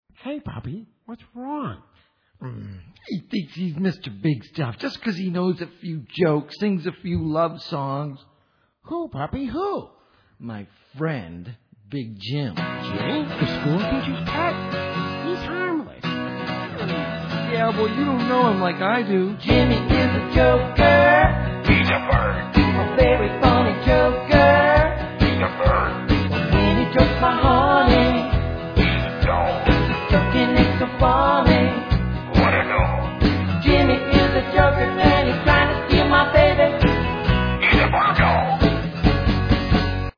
From bouncy and boisterous to warm and furry